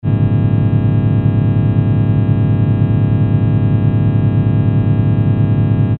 Jetzt haben wir hundert peaks, logarithmisch verteilt.
Die peaks wiederum rücktransformiert:
Die Rücktransformation von isolierten spektralen samples ergibt in der Zeitebene einen Sinusakkord, einen 'Strahlklang', wie das Stockhausen so schön nannte.